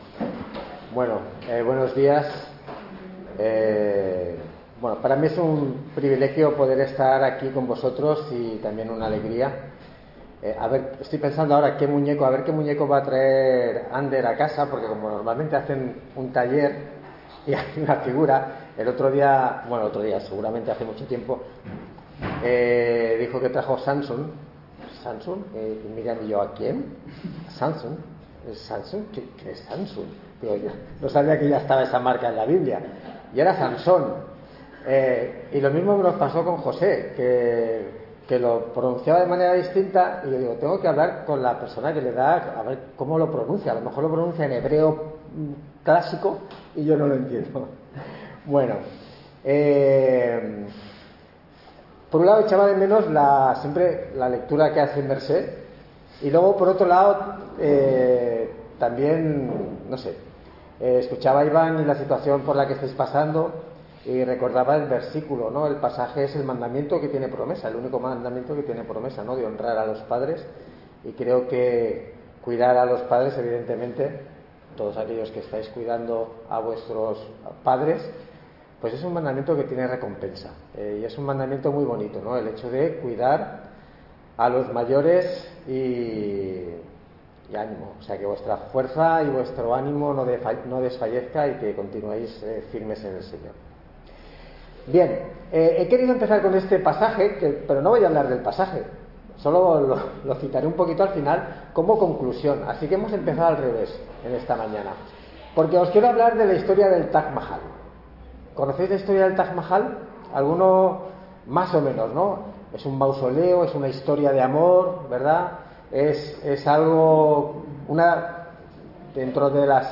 Predicación (27/07/2025)
Service Type: Culto Dominical